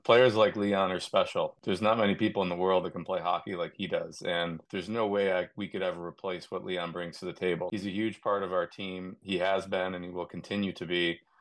Draisaitl and Bowman sat in a news conference this afternoon and Leon spoke about his love for being an Oiler:
GM Stan Bowman spoke briefly about the upcoming challenges they will face regarding cap space having some heavy salaries in his roster but ultimately, he wasn’t willing to let a player like Leon play for a different team: